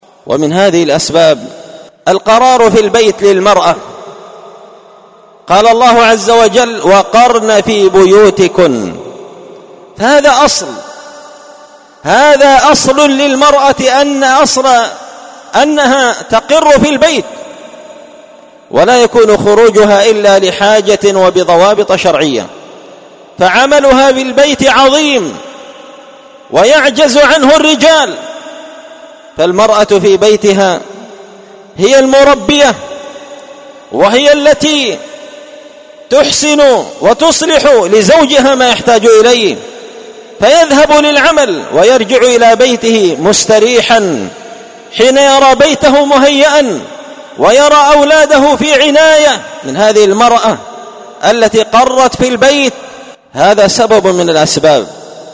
المقتطف الرابع سلسلة مقتطفات من خطبة جمعة بعنوان حراسة الفضيلة وحماية المجتمع من الرذيلة 24 ربيع الثاني 1444هـ
دار الحديث بمسجد الفرقان ـ قشن ـ المهرة ـ اليمن